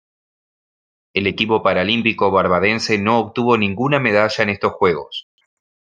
Pronounced as (IPA) /ninˈɡuna/